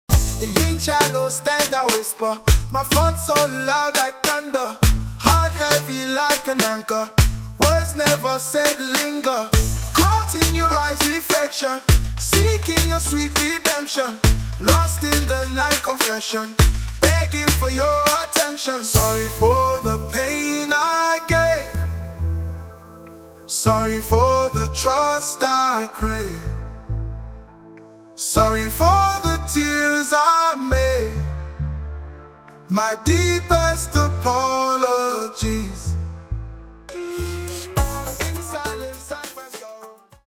Short version of the song, full version after purchase.
An incredible Reggae song, creative and inspiring.